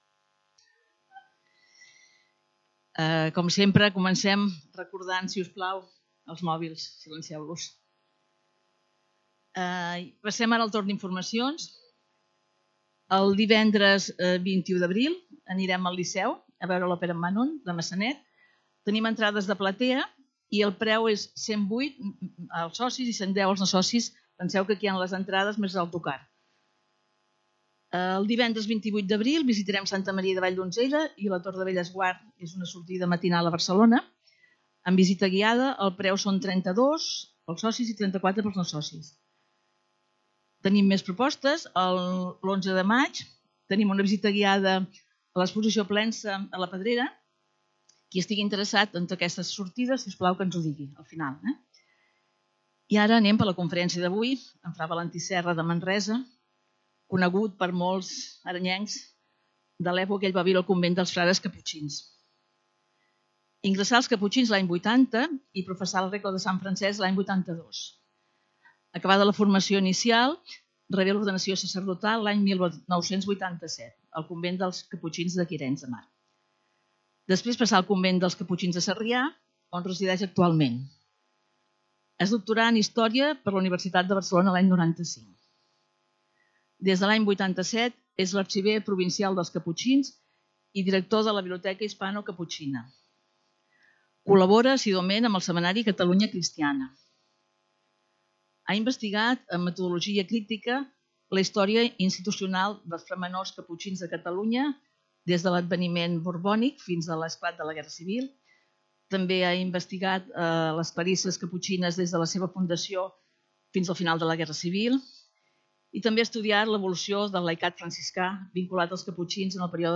Lloc: Centre Cultural Calisay